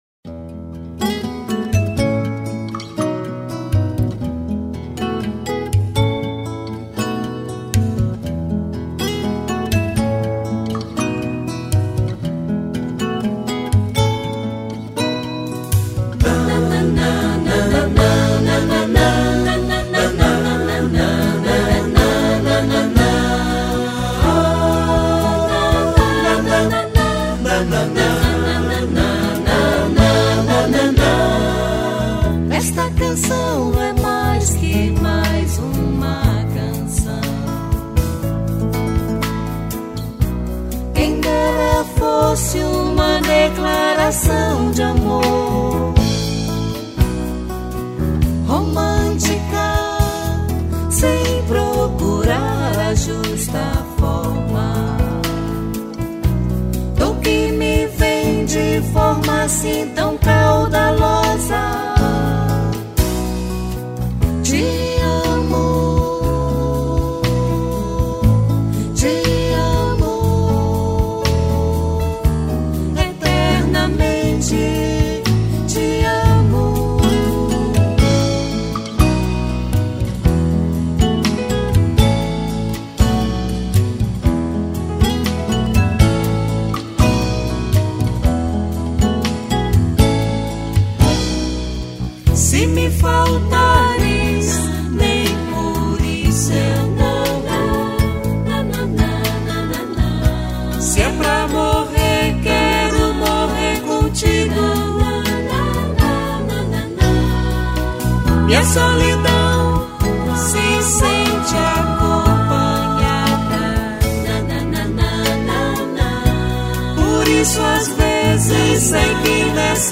193   05:28:00   Faixa:     Mpb